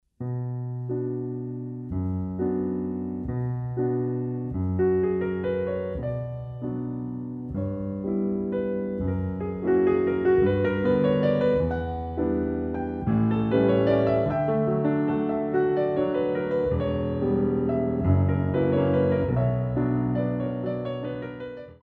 Valse pointe